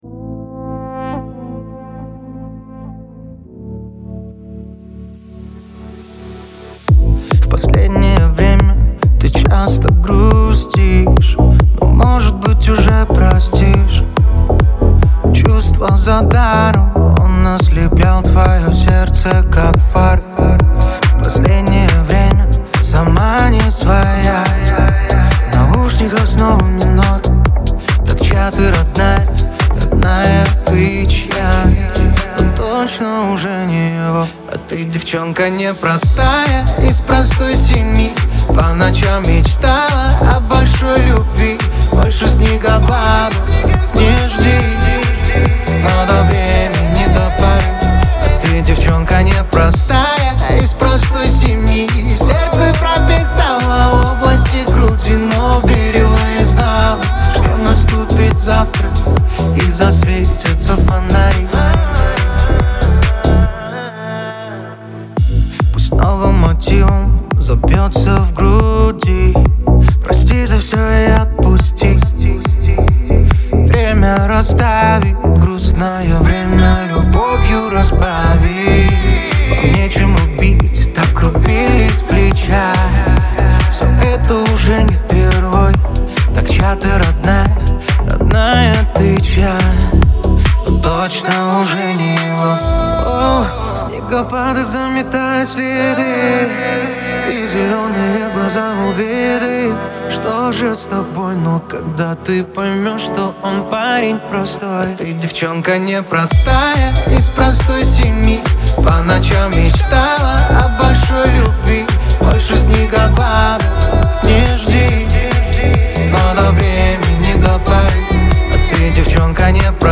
Лирика , грусть , ХАУС-РЭП
эстрада